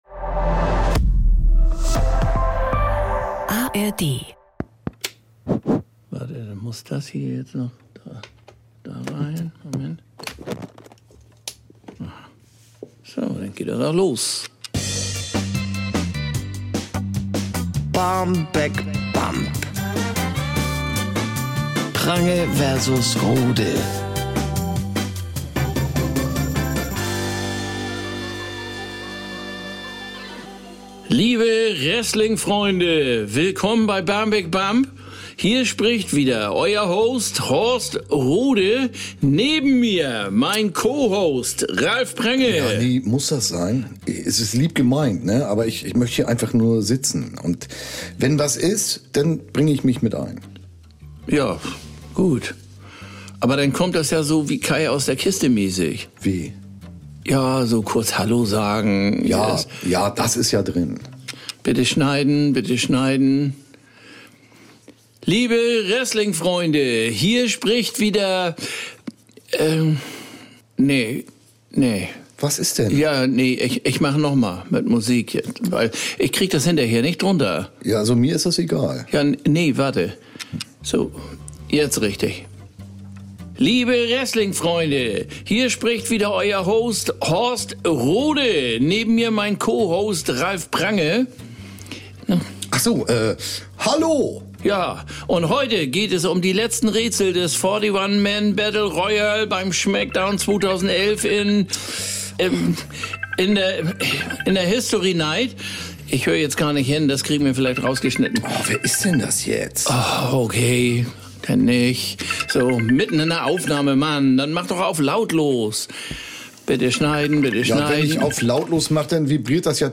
Ralf Prange: Bjarne Mädel Horst Rohde: Olli Dittrich Sprecherin: Doris Kunstmann